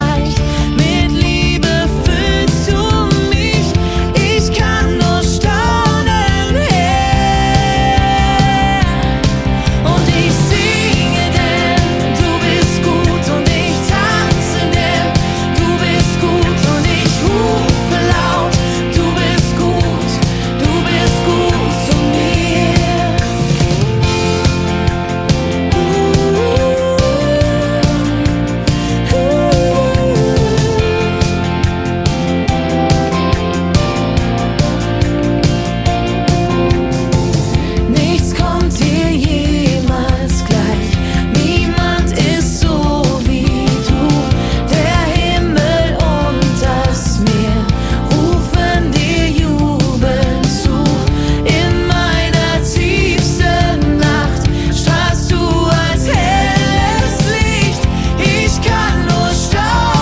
Text) Worship 0,99 €